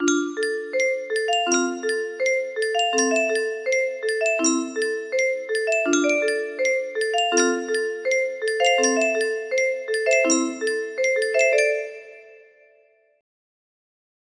verse melody